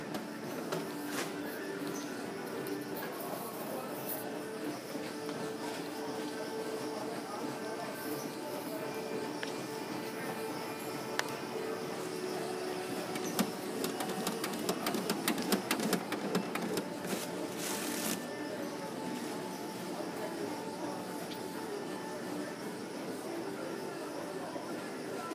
Field Recording 9
Sounds heard, gym equipment, paper towel machine, faint voices, background music